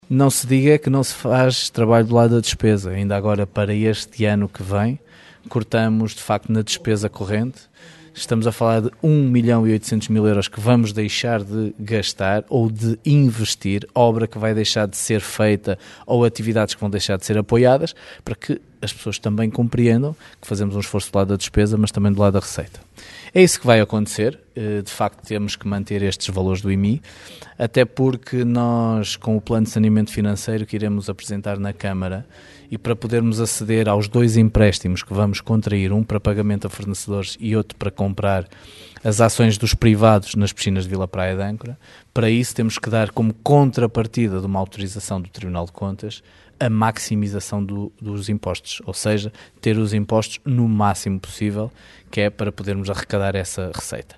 Miguel Alves a justificar a aplicação da taxa máxima no Imposto Municipal sobre Imóveis.